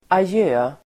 Ladda ner uttalet
adjö interjektion (avskedsfras), goodbye!, farewell!Uttal: [aj'ö:] Synonymer: farväl, hej då